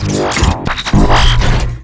Lately I've been trying to achieve this particular high pitched sound with many different methods but none of them did the job.
To me, it sounds like it could be a mix of two processes: the buzzy portion could be the result of a delay or HarmonicResonator with a very short delay or frequency.
The fluttery part sounds like it could be a drum sample being scratched.